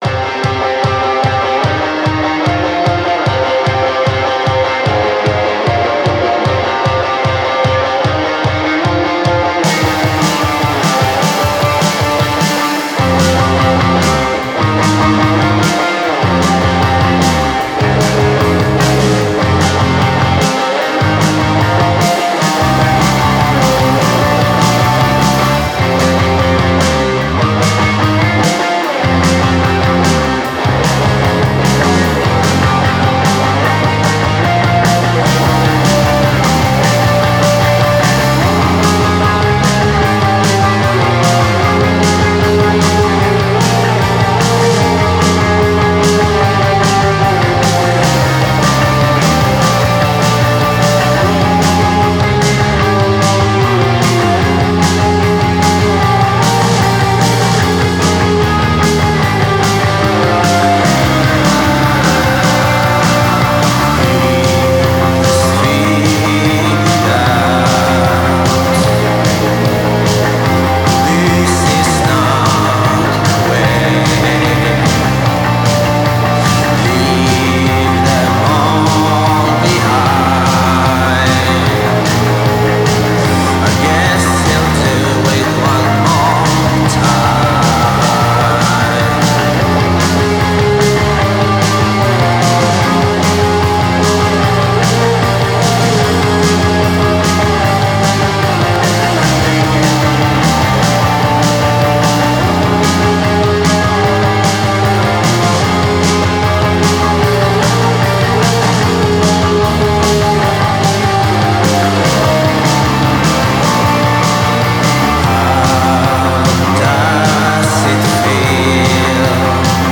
solid Swedish goth rock